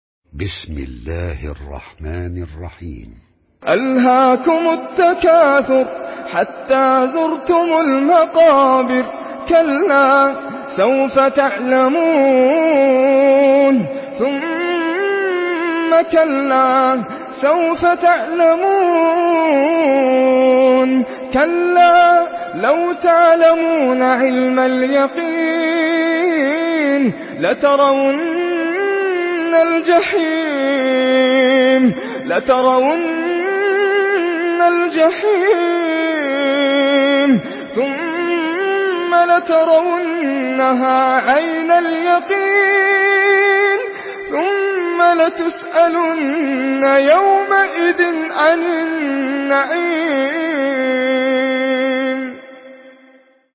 Surah التكاثر MP3 by ناصر القطامي in حفص عن عاصم narration.
مرتل حفص عن عاصم